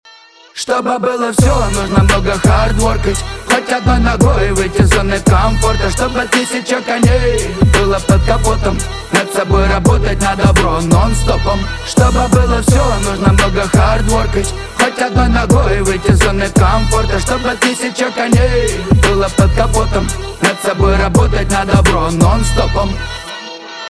русский рэп
битовые , басы